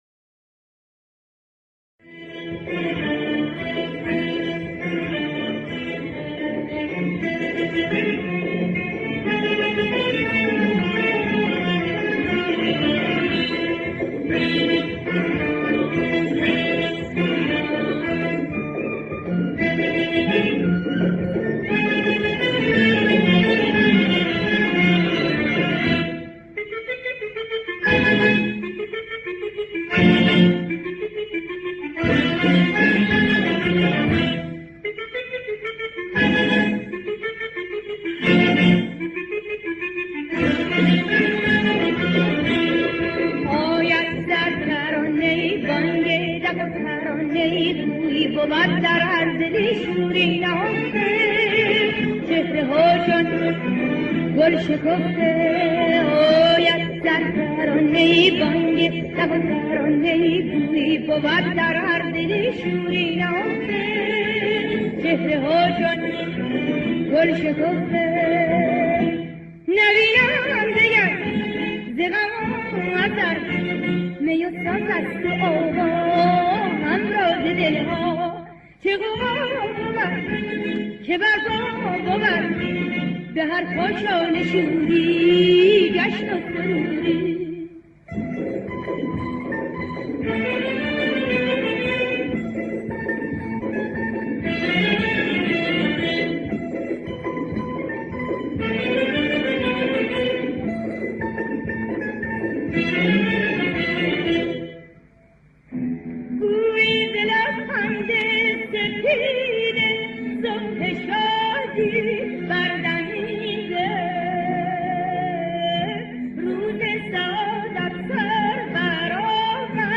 دستگاه: همایون
در مایه: اصفهان